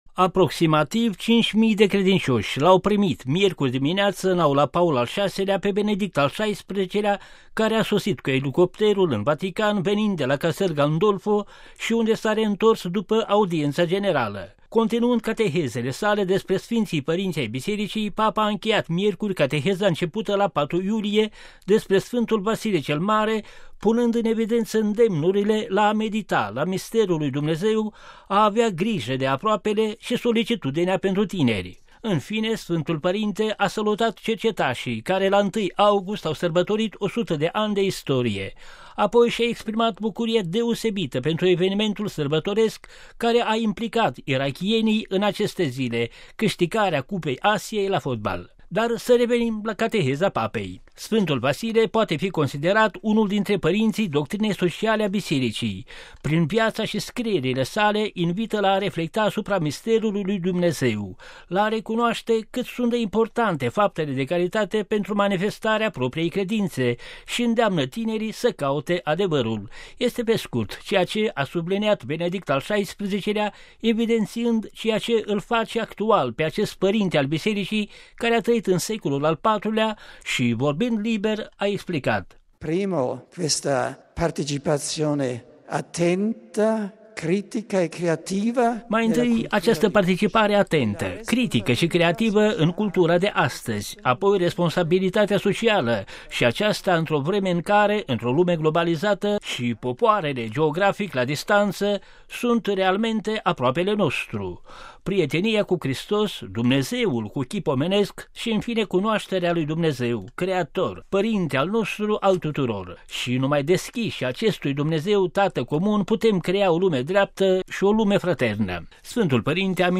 Ascultaţi şi binecuvântarea apostolică invocată de Papa la sfârşitul audienţei imediat după intonarea în cor a rugăciunii „Tatăl nostru”.